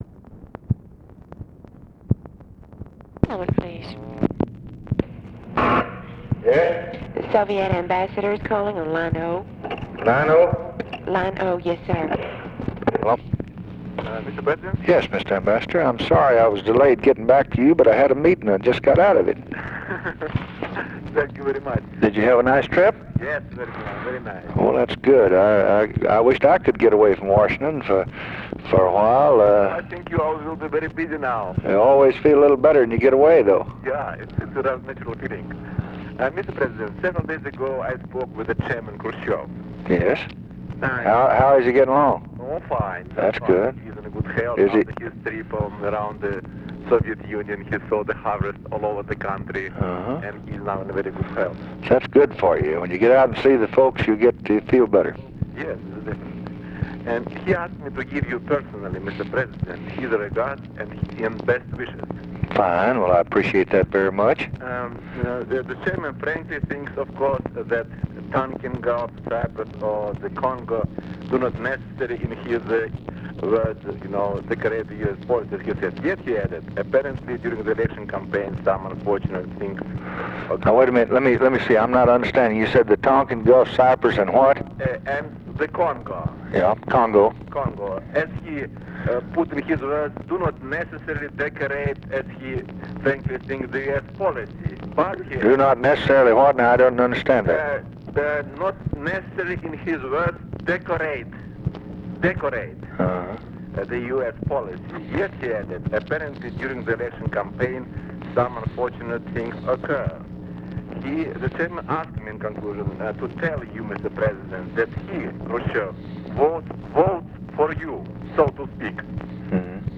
Conversation with ANATOLY DOBRYNIN, September 4, 1964
Secret White House Tapes